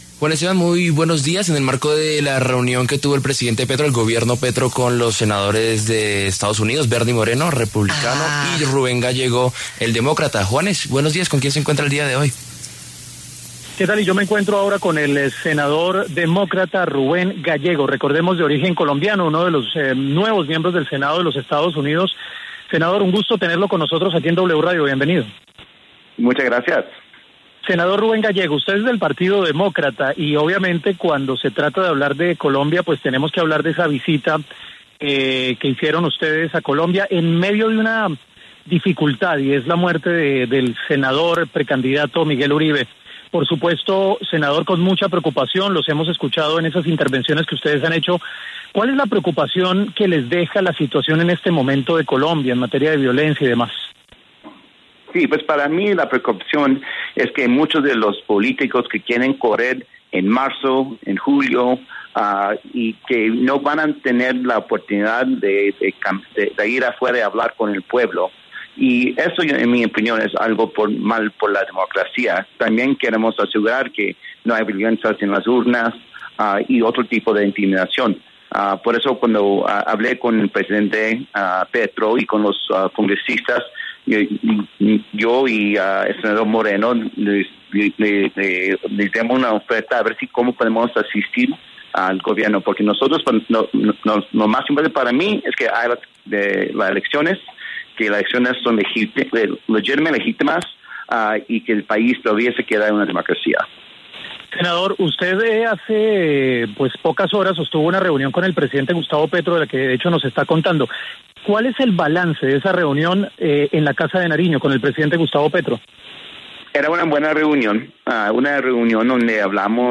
Rubén Gallego, congresista estadounidense, habló en W Fin de Semana sobre la reunión con el presidente Gustavo Petro y las relaciones de su país con Colombia.